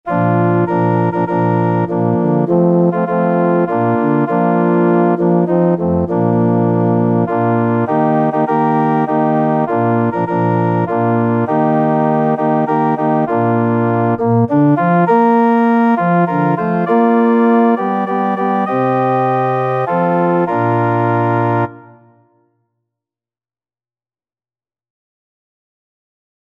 Classical Trad. The Glorious Gates of Righteousness Organ version
3/4 (View more 3/4 Music)
Bb major (Sounding Pitch) (View more Bb major Music for Organ )
Organ  (View more Intermediate Organ Music)
Christian (View more Christian Organ Music)